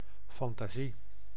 Ääntäminen
IPA : /ɪˌmædʒəˈneɪʃən/